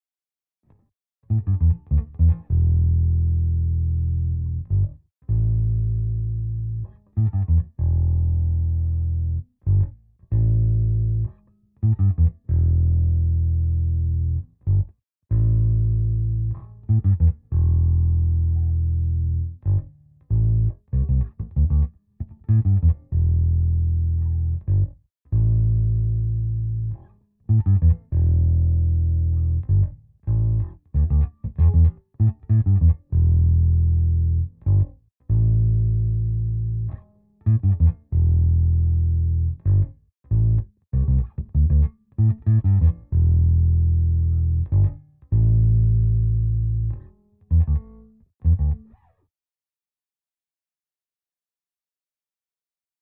Nahráváno na Fender Precision American Vintage 2.
Nahrávky bez bicích:
Wet 1966 bez bicich